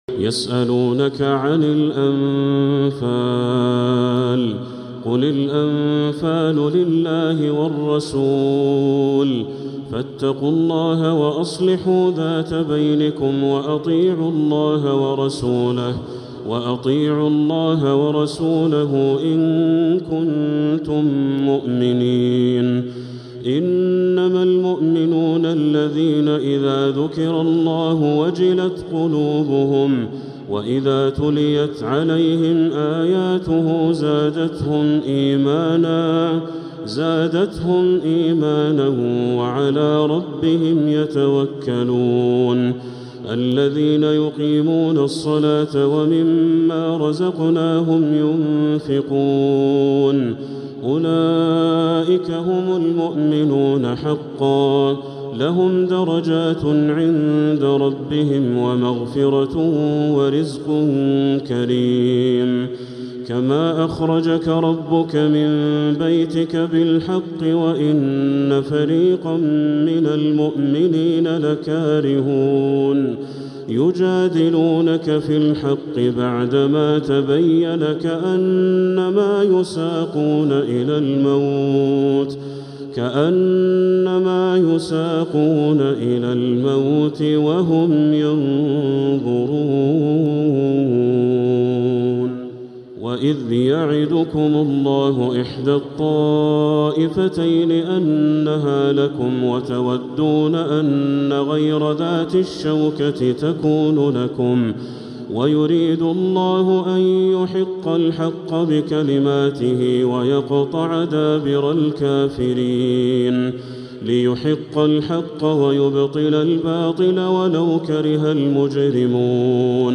| سورة الأنفال كاملة للشيخ بدر التركي من المسجد الحرام | Surat Ar-Anfal Badr Al-Turki > السور المكتملة للشيخ بدر التركي من الحرم المكي 🕋 > السور المكتملة 🕋 > المزيد - تلاوات الحرمين